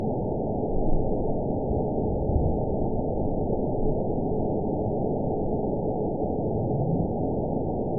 event 920673 date 04/02/24 time 23:05:31 GMT (1 year, 1 month ago) score 8.85 location TSS-AB03 detected by nrw target species NRW annotations +NRW Spectrogram: Frequency (kHz) vs. Time (s) audio not available .wav